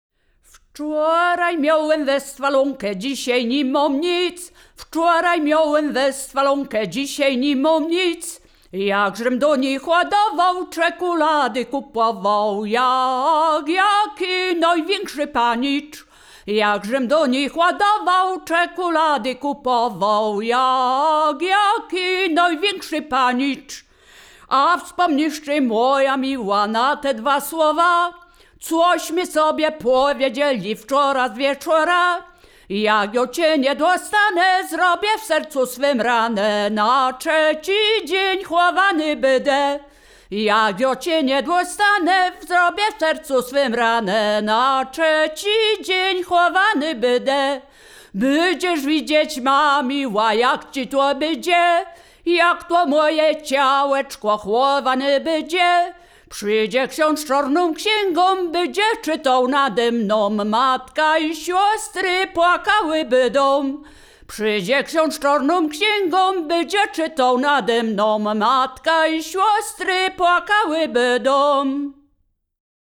liryczne miłosne